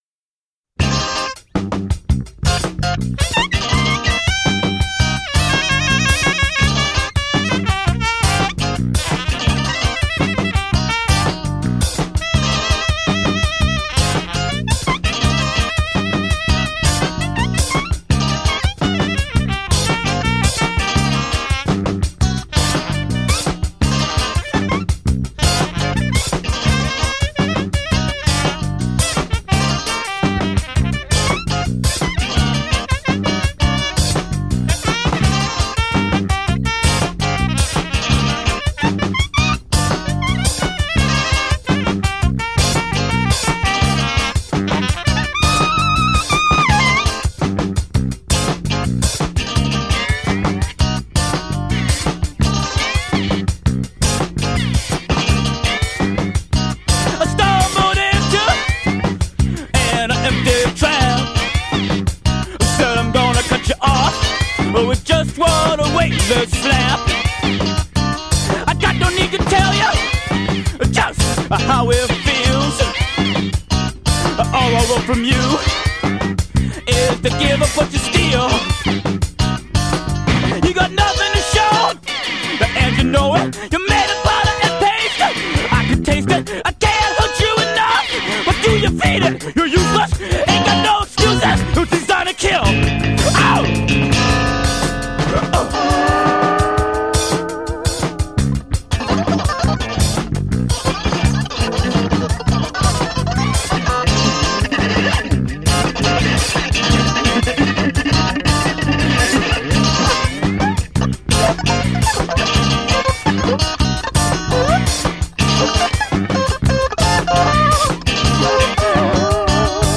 An office worker in Memphis, Tennessee plays music from his record collection, with a focus on post-punk, electronic music, dub, and disco.